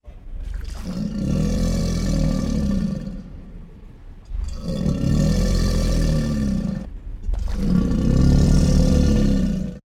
crocodile-sound